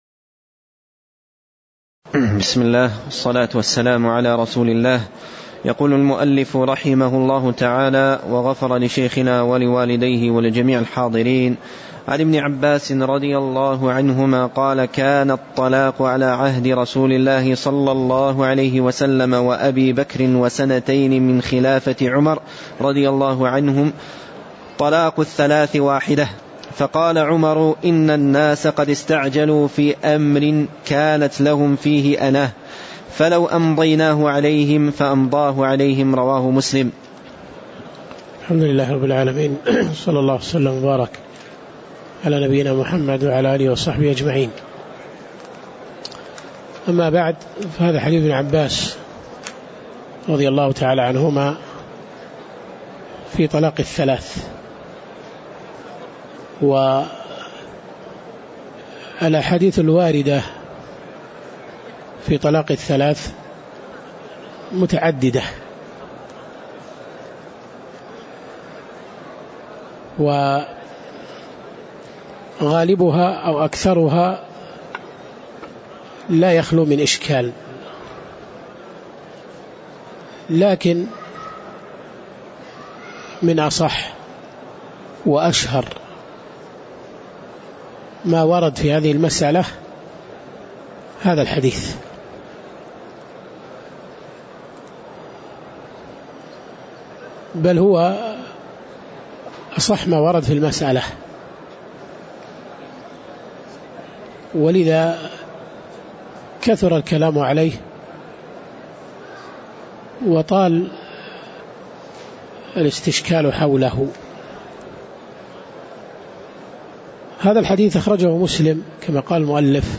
تاريخ النشر ٥ ربيع الثاني ١٤٣٨ هـ المكان: المسجد النبوي الشيخ